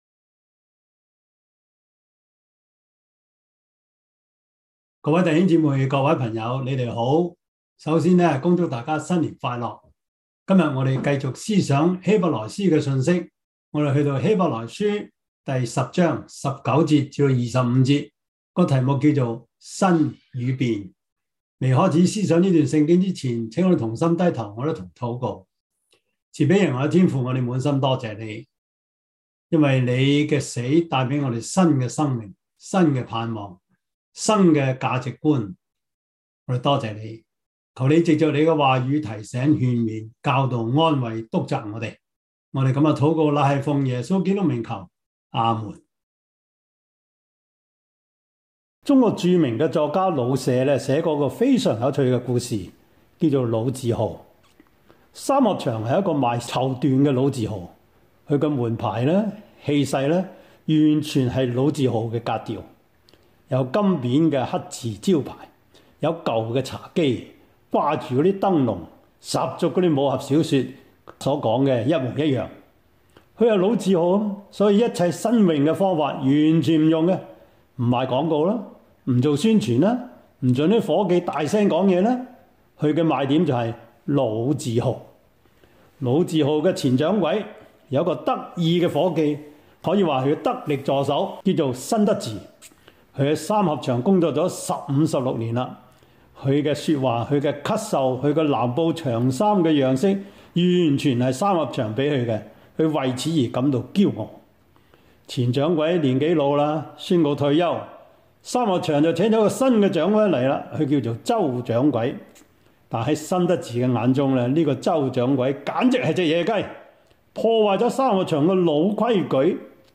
Service Type: 主日崇拜
Topics: 主日證道 « 興起發光 第十八課: 殉道的宣教策略 »